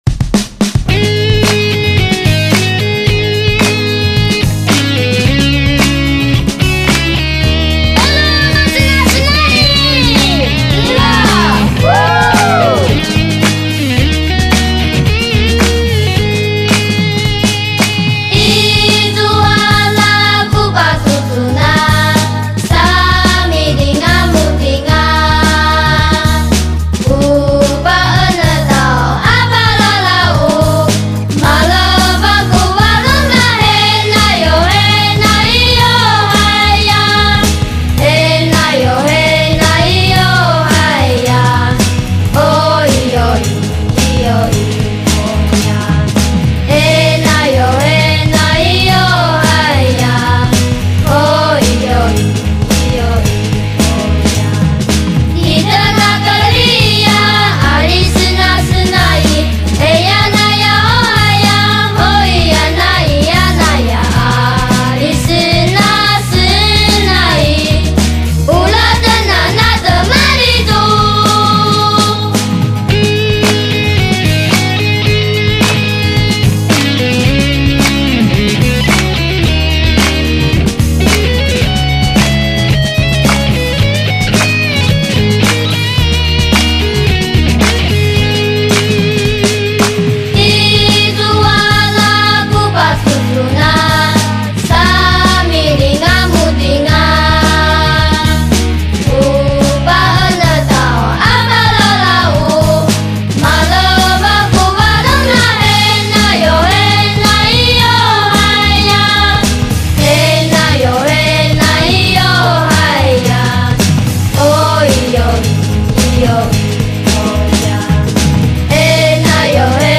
原住民音乐
吉他
二胡
中音直笛
录音室：高雄亚洲数位录音室
给您自然、感动、飞扬的声音